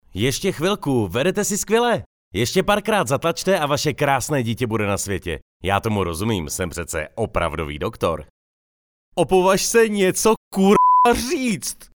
Profesionální dabing - mužský hlas